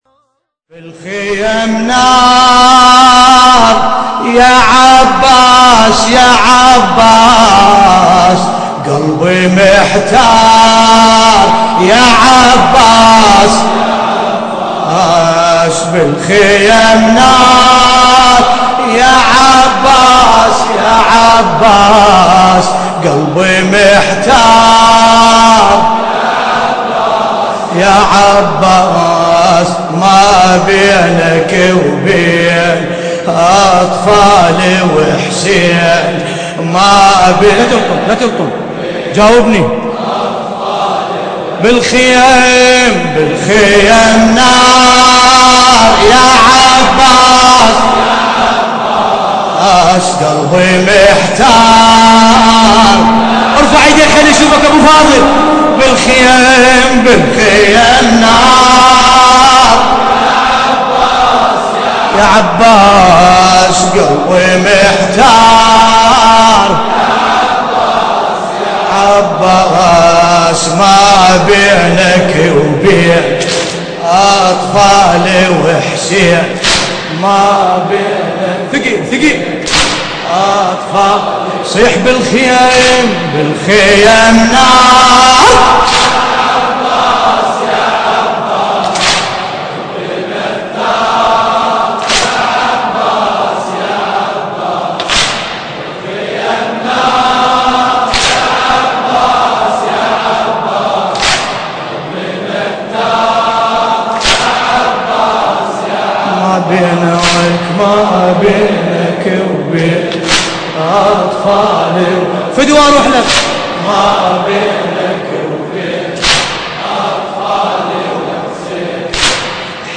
الليلة السابع من شهر محرم الحرام عام 1434 هـ - الكويت .